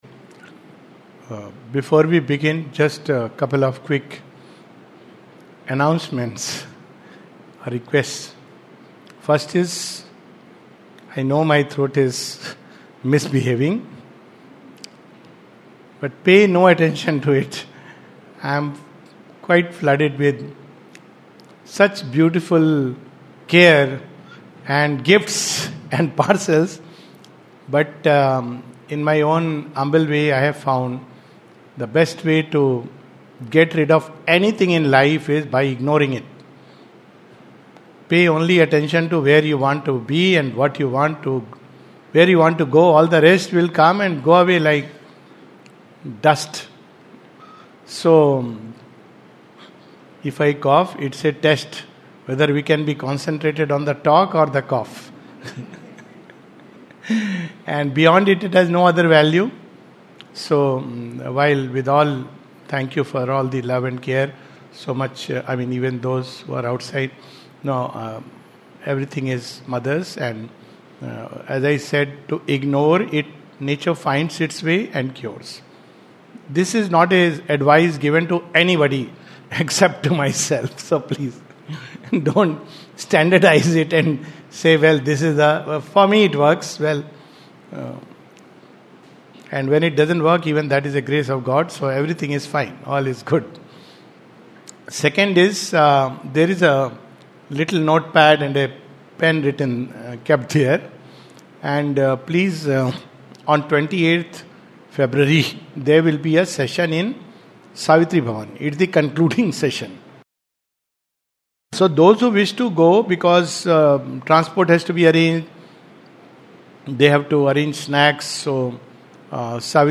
This talk takes up the first few passages of Chapter 24 of Book 1 of The Life Divine. A talk